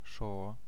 Ääntäminen
Etsitylle sanalle löytyi useampi kirjoitusasu: Ŝ S Ääntäminen letter name: IPA: /ʃo/ phoneme: IPA: /ʃ/ Haettu sana löytyi näillä lähdekielillä: esperanto Käännöksiä ei löytynyt valitulle kohdekielelle.